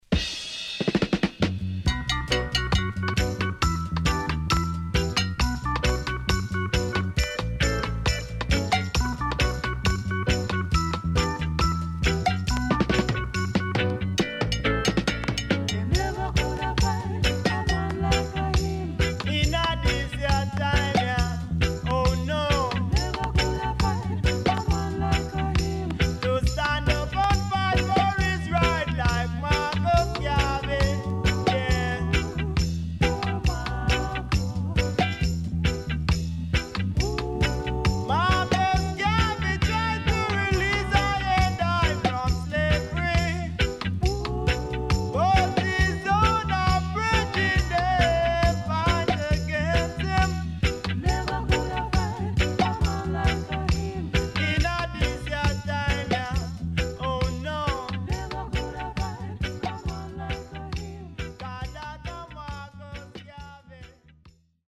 Killer Roots Vocal